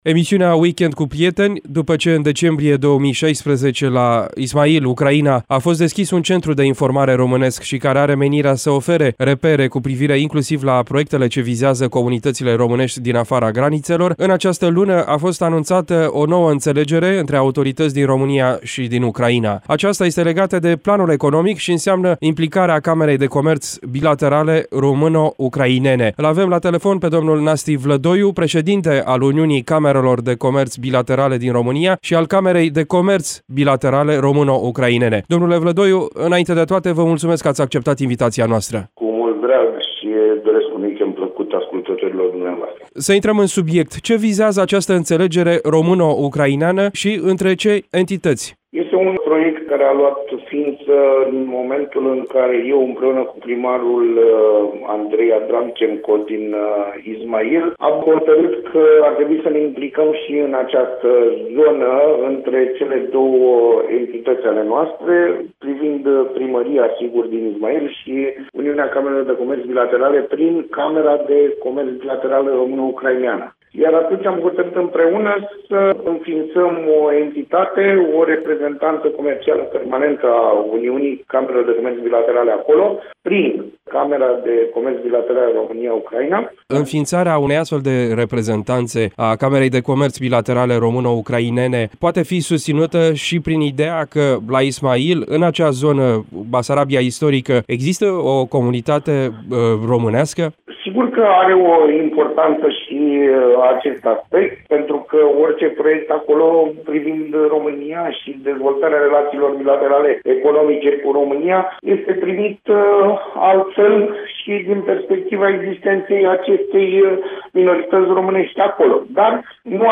Într-un interviu